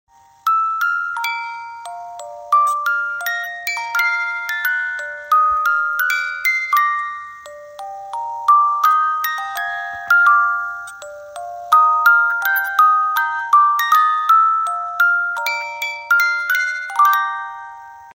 Cover , Romántico